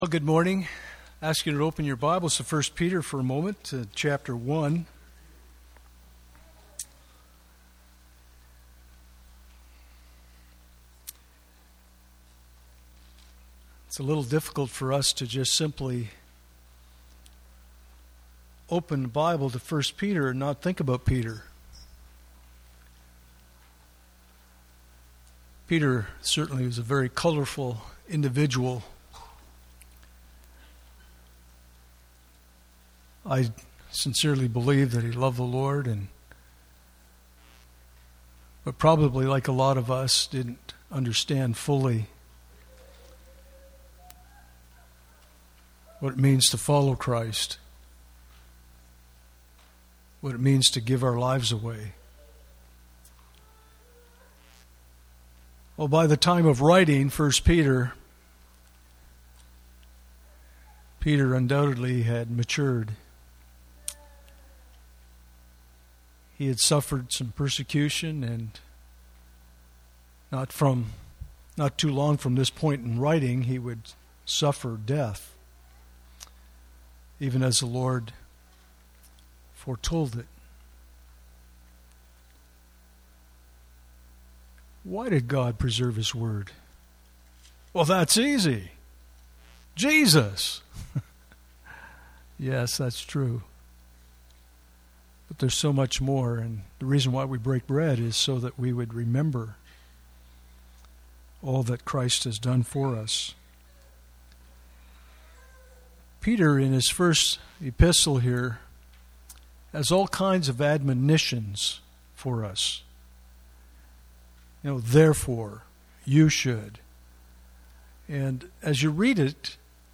Communion Service
Communion Passage: 1 Peter 2:4-9 Service Type: Sunday Morning « Terrible Times Ahead?